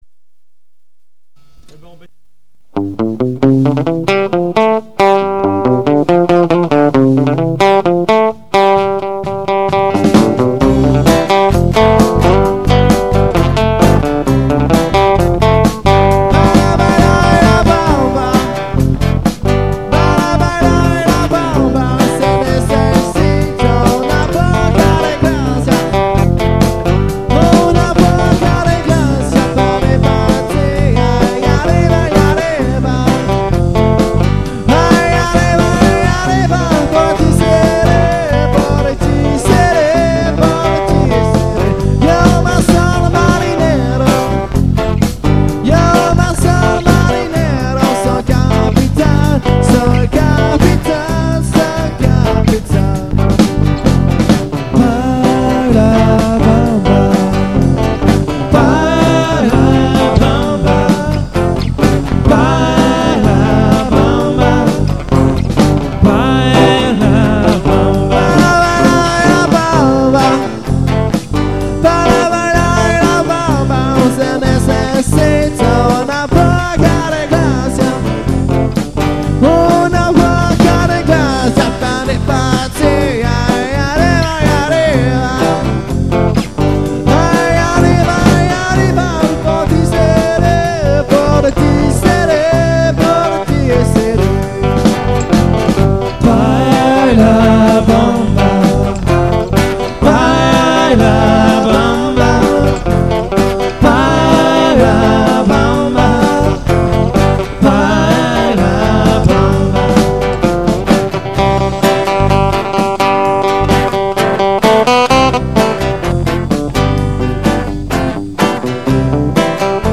Guitare rythmique, chant
Saxophone, Basse
Batterie & Percussions
enregistré en live dans le garage